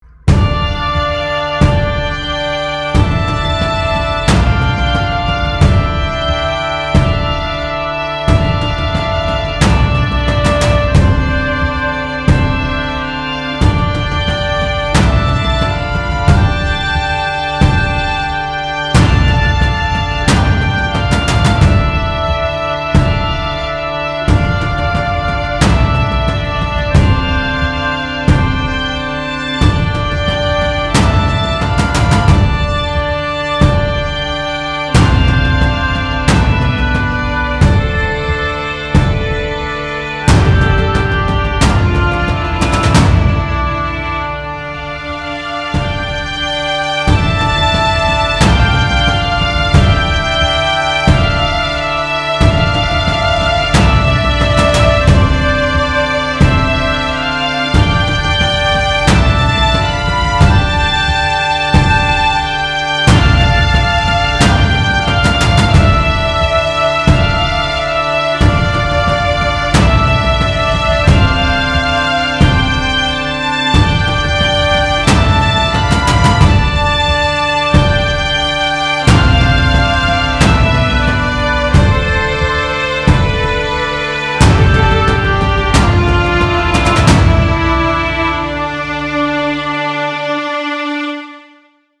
Fantasy music, maybe good for RPG something, hopefully.